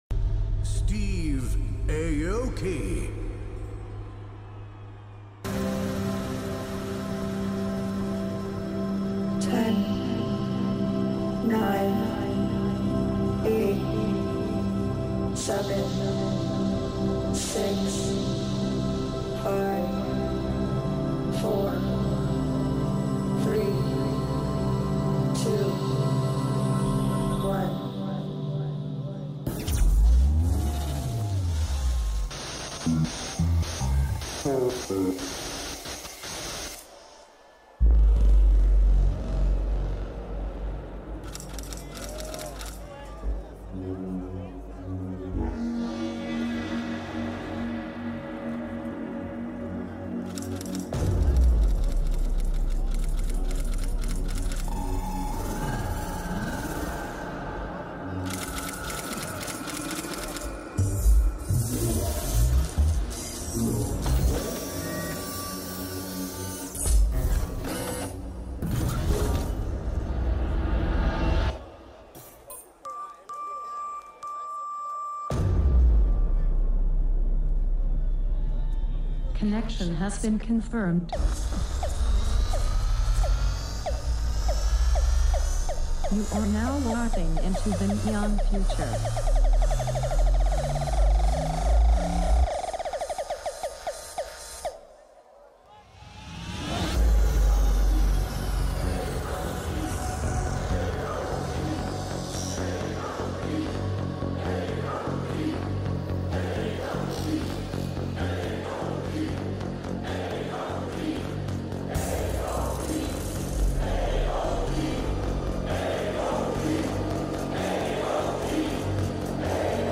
Also find other EDM Livesets, DJ Mixes and Radio Show
Liveset/DJ mix